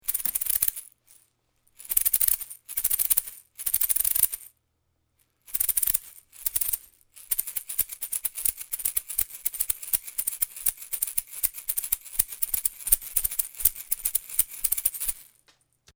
Der durch Stahlkügelchen im inneren erzeugte Klang ist relativ leise - nichts für`s große Orchester also, aber ideal für ein kleines zu Hause.
Hörbeispiel Ei-Shake